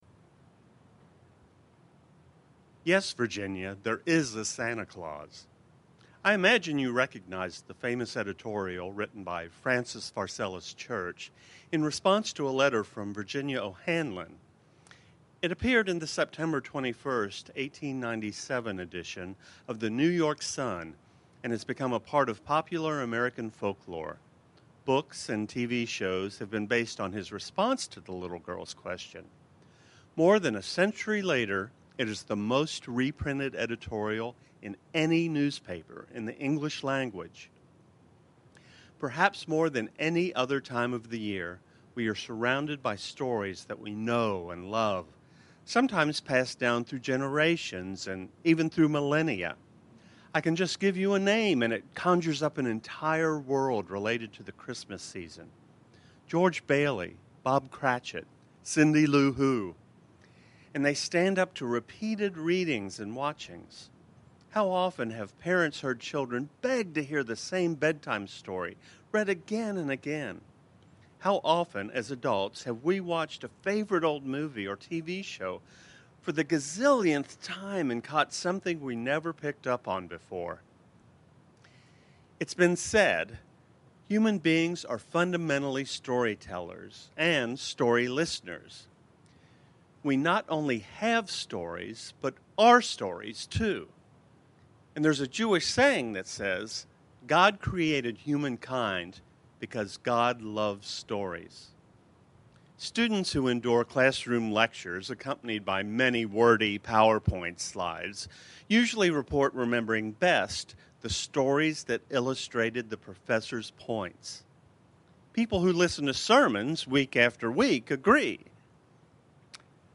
Reading: Is there a Santa Claus?
Sermon-The-Rest-of-the-Story.mp3